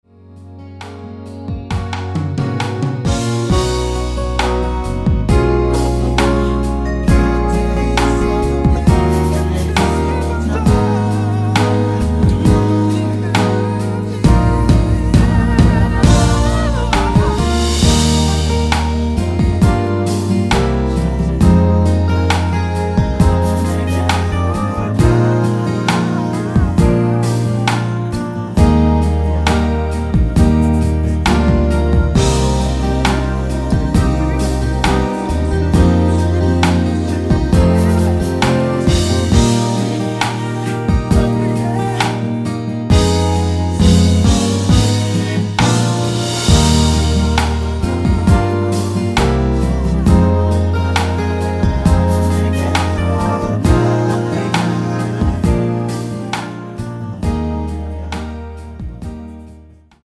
원키 코러스 포함된 MR 입니다.(미리듣기 참조)
Eb
앞부분30초, 뒷부분30초씩 편집해서 올려 드리고 있습니다.